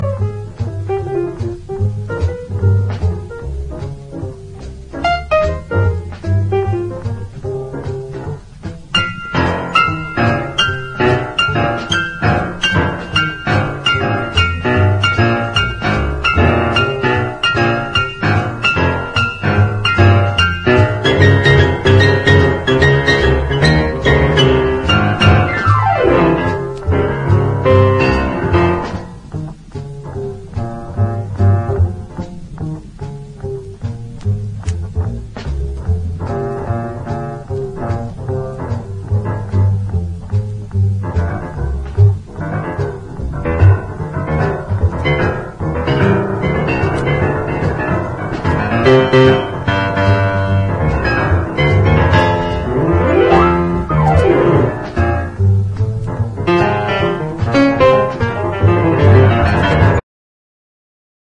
ROCK / 70'S / SWAMP ROCK / PSYCHEDELIC ROCK / COUNTRY ROCK
71年サイケデリック風味ハード・カントリー・ロック唯一作！